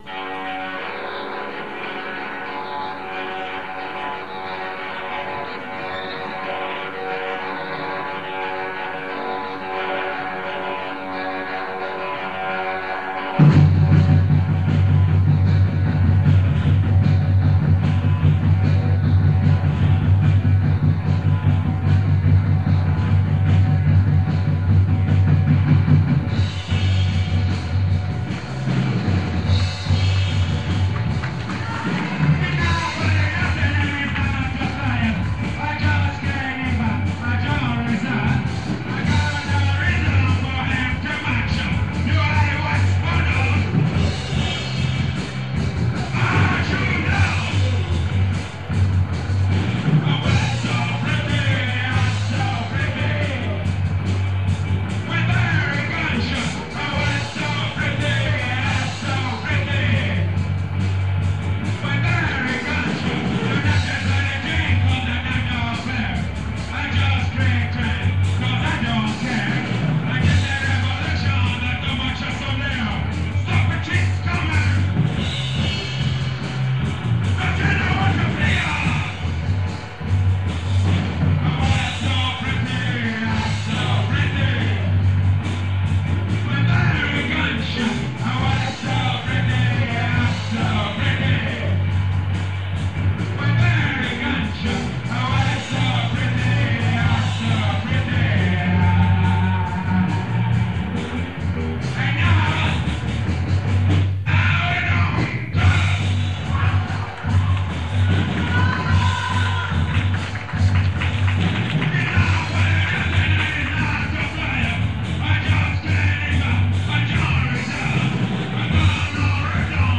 Il secondo concerto della